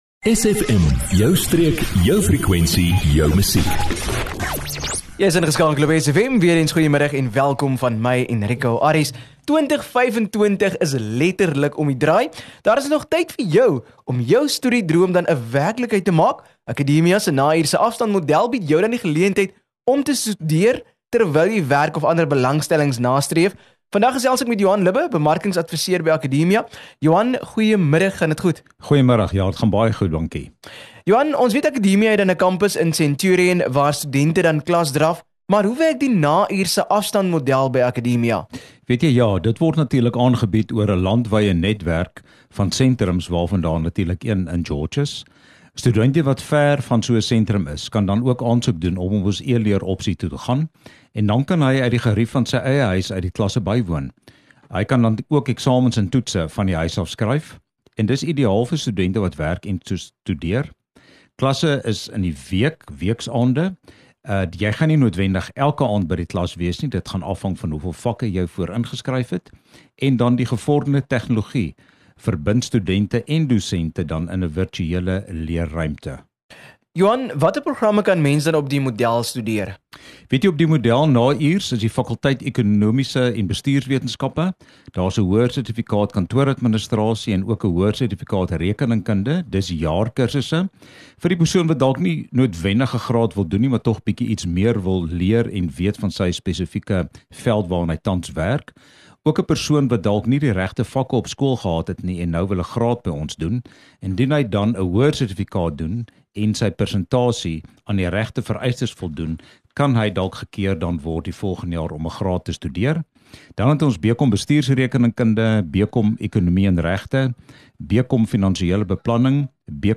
6 Dec Akademia Promosie - Onderhoud 02 Desember 2024